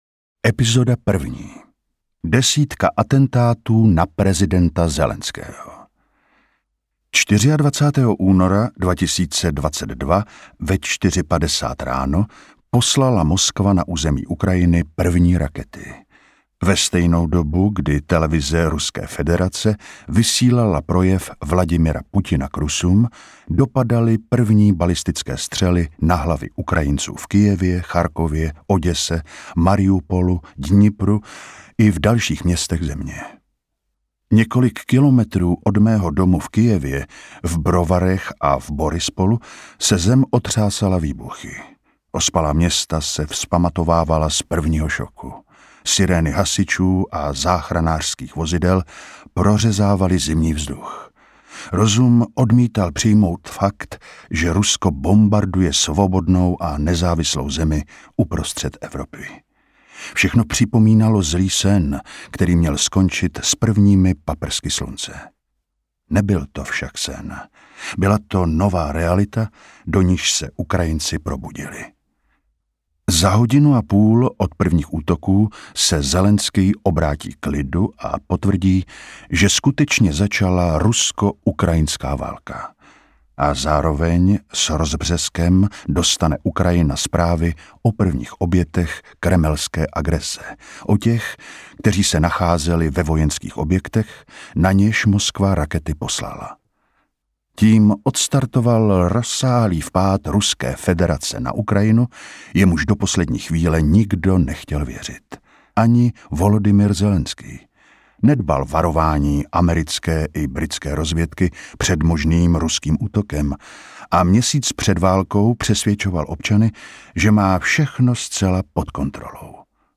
Volodymyr Zelenskyj (audiokniha)